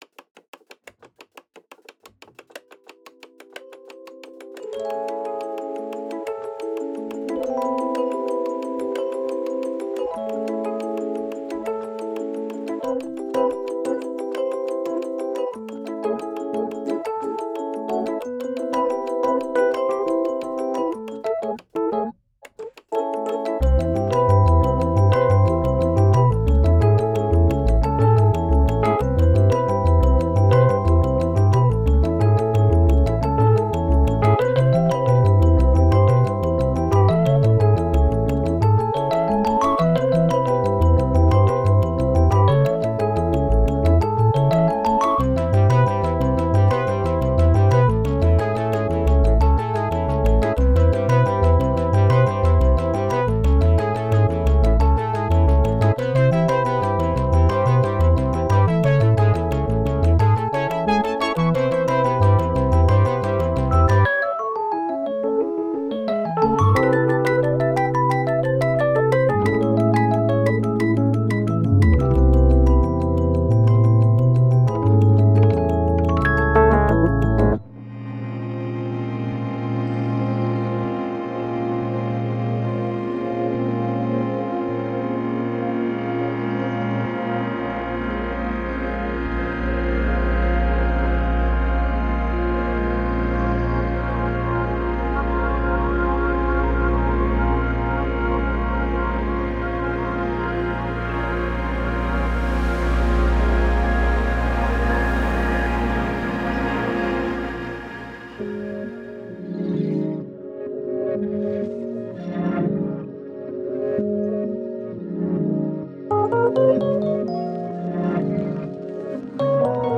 70s electro-mechanical keyboard